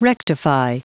Prononciation du mot rectify en anglais (fichier audio)
Prononciation du mot : rectify